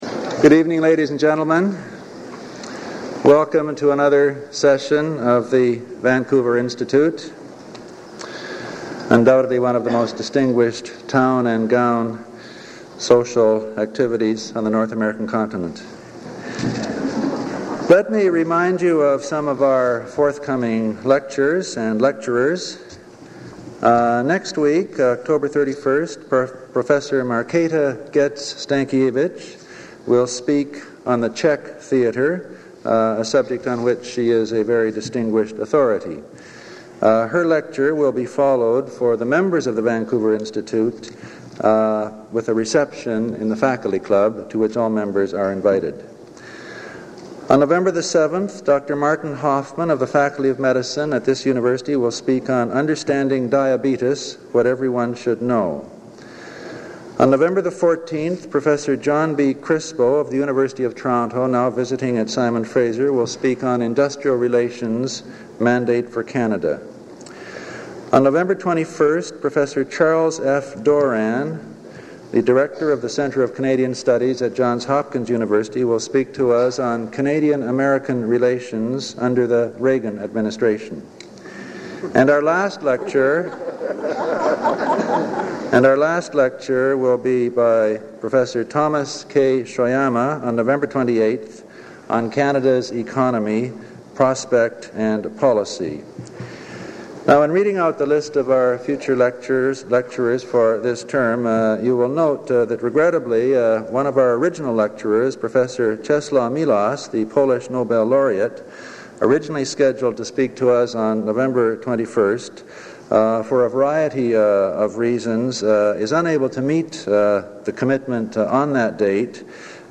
Item consists of a digitized copy of an audio recording of a Cecil and Ida Green lecture delivered at the Vancouver Institute by Conor Cruise O'Brien on October 19, 1981.